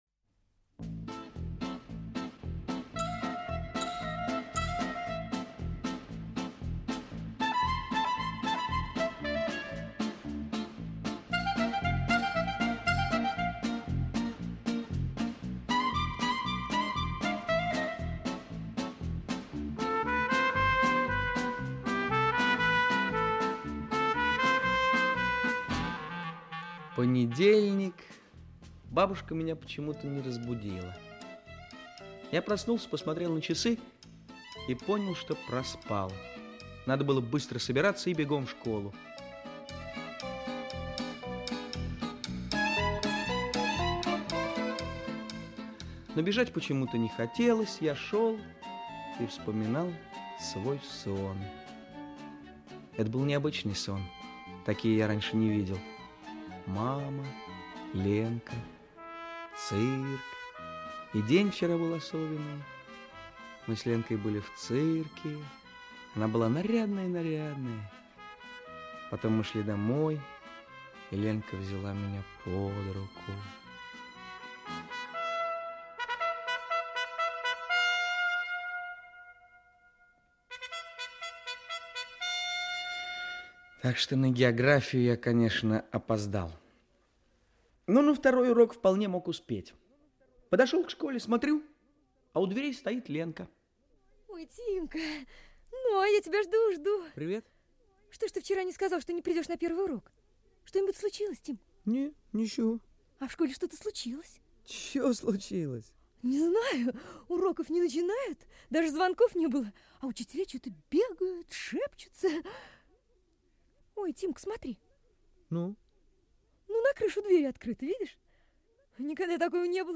Окончание радиопостановки по повести о мальчике Тиме Таирове и его сложных взаимоотношениях с отцом.